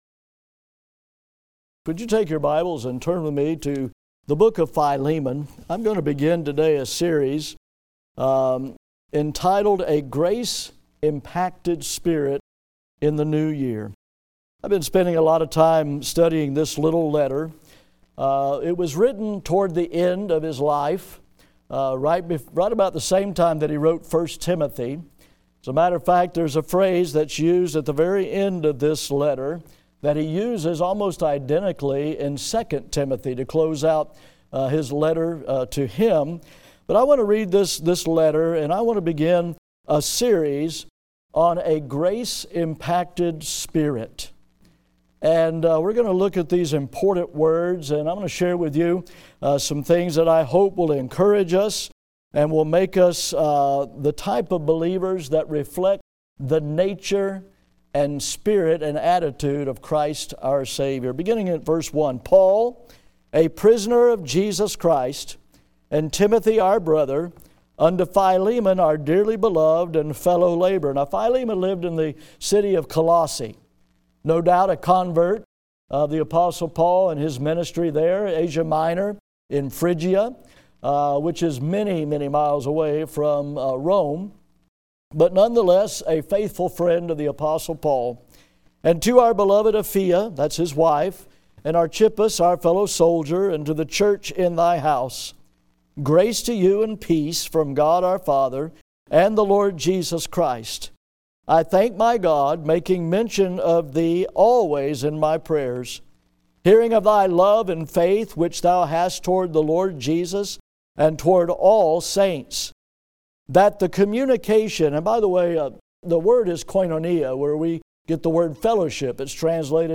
GRACE BIBLE CHURCH Audio Sermons